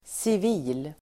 Uttal: [siv'i:l]